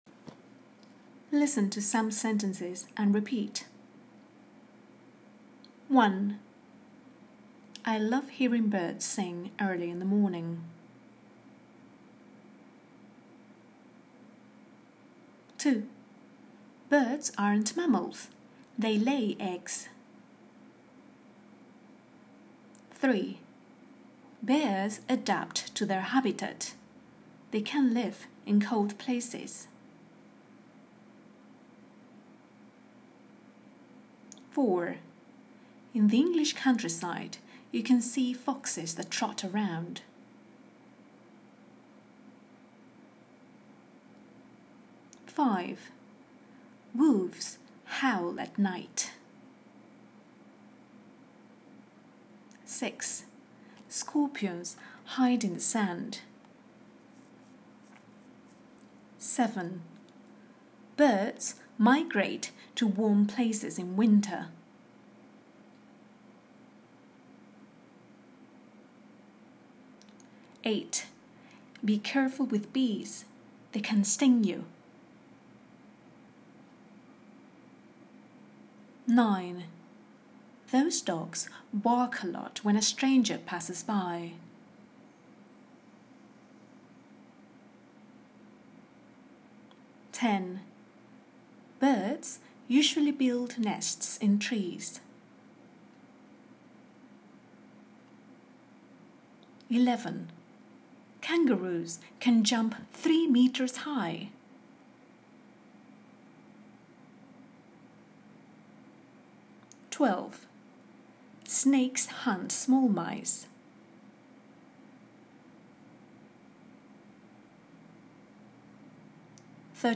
Click PLAY below to listen to the pronunciation of the sentences above.